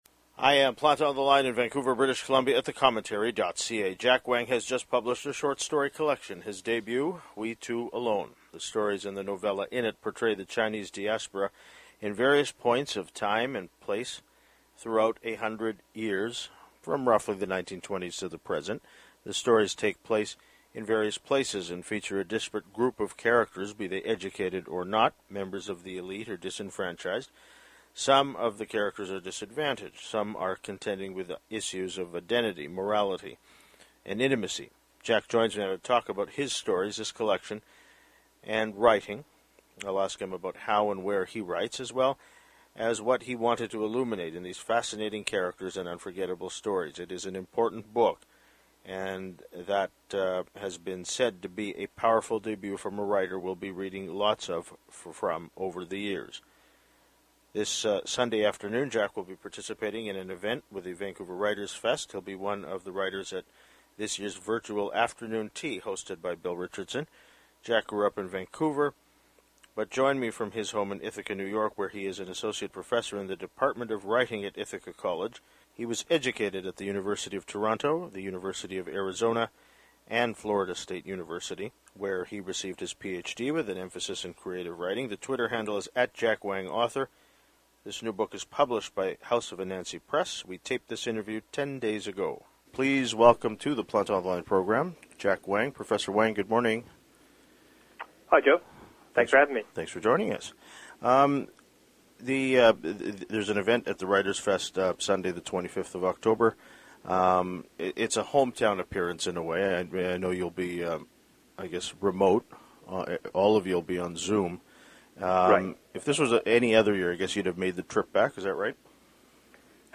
We taped this interview ten days ago.